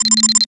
program_deny.wav